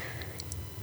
Attached is you gasping for air in the room tone segment when you’re supposed to be holding your breath. I boosted the volume for testing.